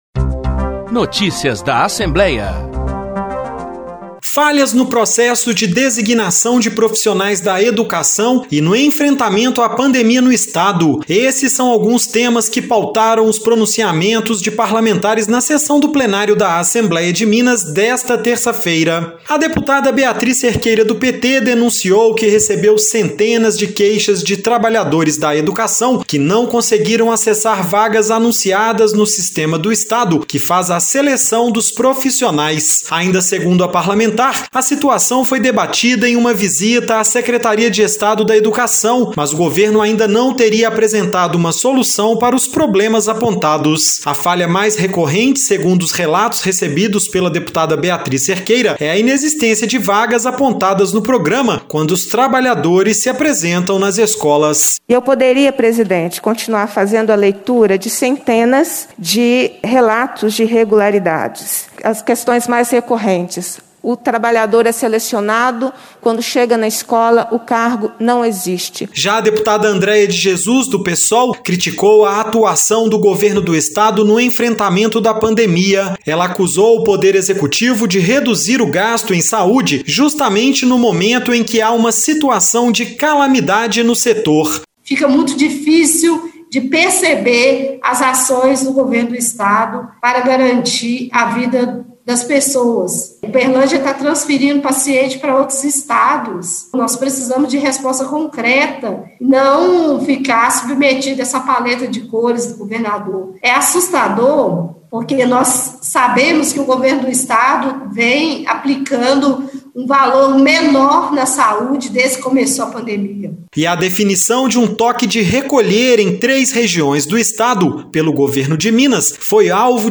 Decisão do ministro Edson Fachin, do STF, sobre processos de Lula foi tema de pronunciamentos na Reunião Ordinária de Plenário